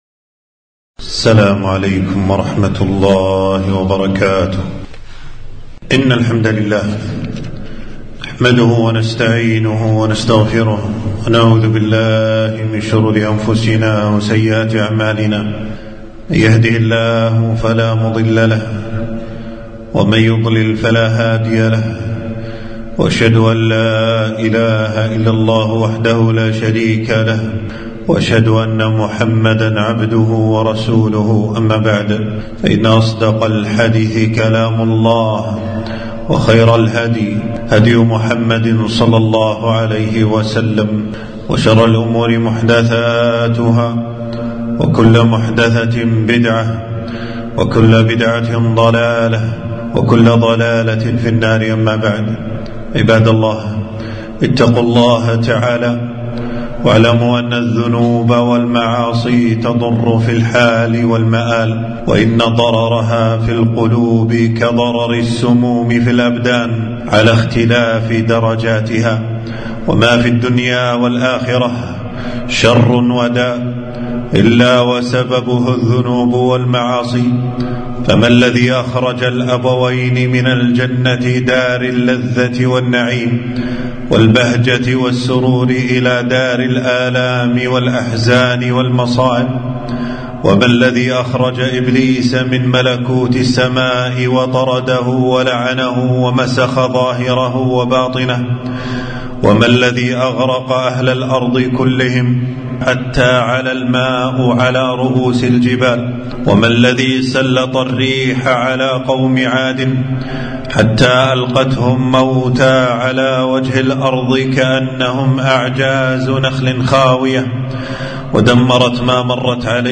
خطبة - خطورة الذنوب والمعاصي